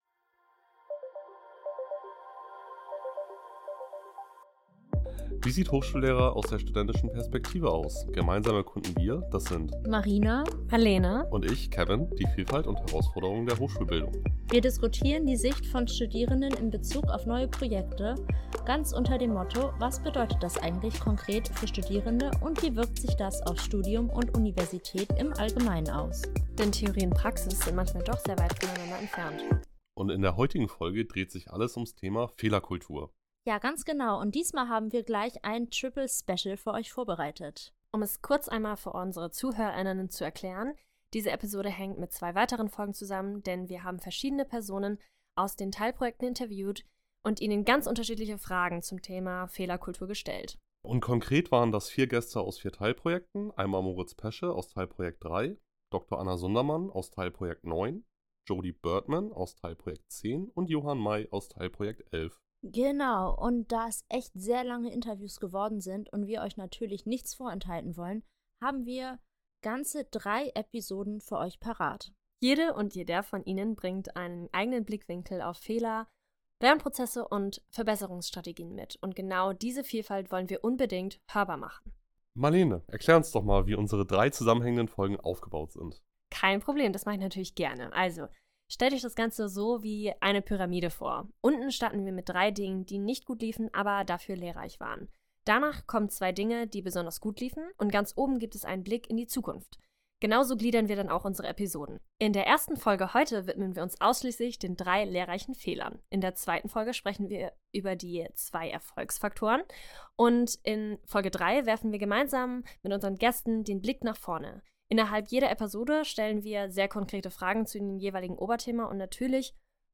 In dieser Folge nehmen wir euch mit in die Welt der Fehlerkultur an Hochschulen. Gemeinsam mit vier Gästen aus dem Projekt DigiTaL sprechen wir über Situationen, in denen etwas nicht wie geplant lief, aber am Ende trotzdem weitergebracht hat.